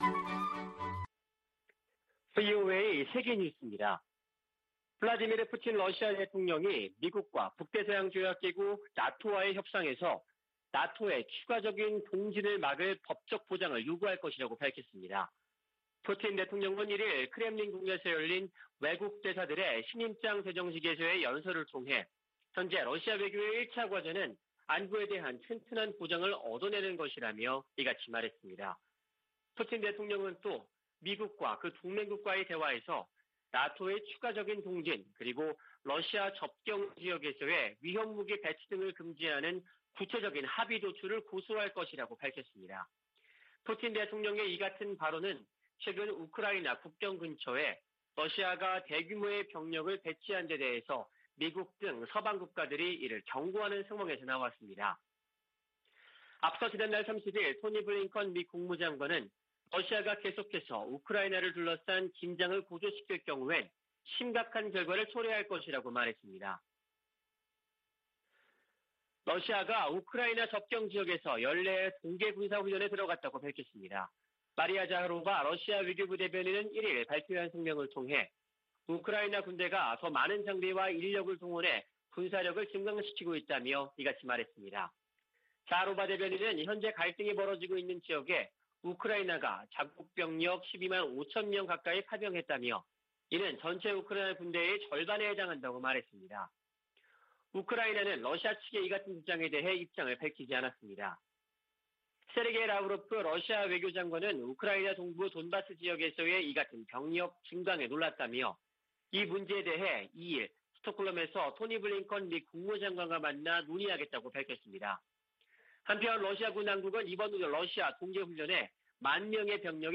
VOA 한국어 아침 뉴스 프로그램 '워싱턴 뉴스 광장' 2021년 12월 2일 방송입니다. 11월 중에 재개될 가능성이 거론됐던 북-중 국경 개방이 무산된 것으로 보입니다. 올해는 9년 만에 미국의 대북 독자 제재가 한 건도 나오지 않은 해가 될 가능성이 높아졌습니다. 한국전 실종 미군 가족들이 미국 정부에 유해 발굴 사업을 정치적 사안과 별개로 추진하라고 요구했습니다.